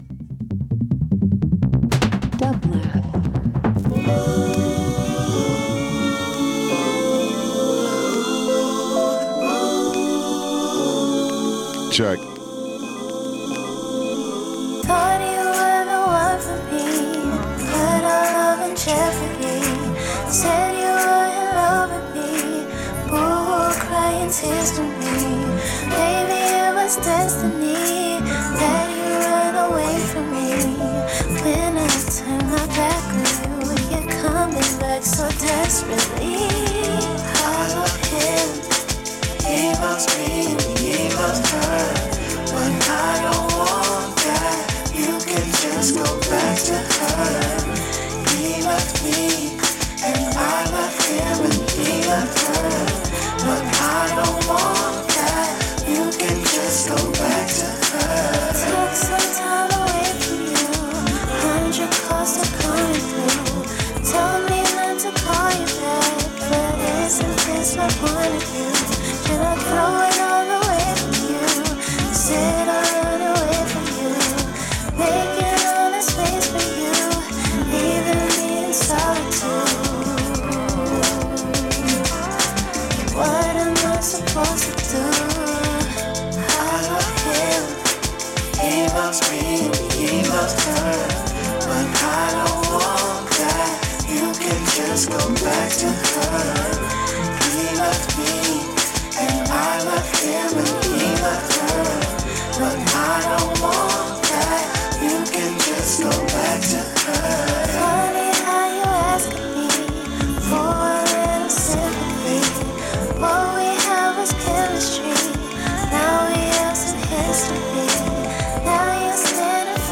Alternative R&B Soul